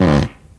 文件夹里的屁 " 屁 43
描述：从freesound上下载CC0，切片，重采样到44khZ，16位，单声道，文件中没有大块信息。
Tag: 喜剧 放屁 效果 SFX soundfx 声音